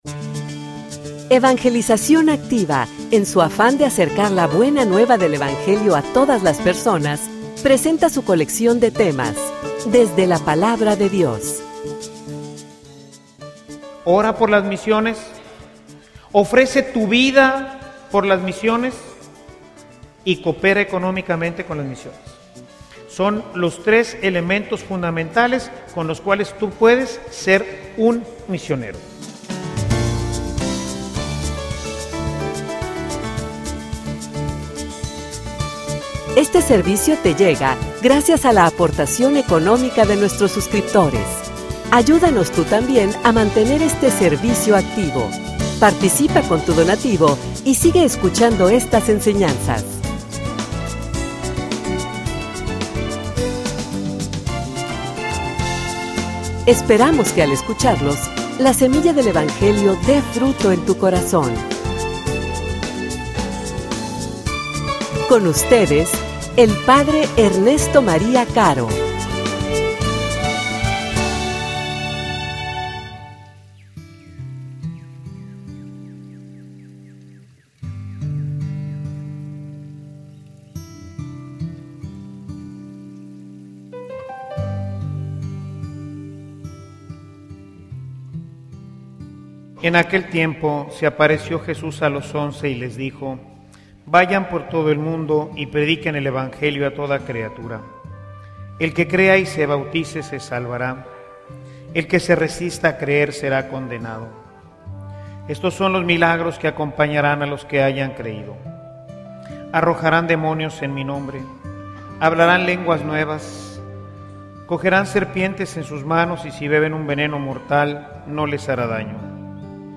homilia_Quien_ira.mp3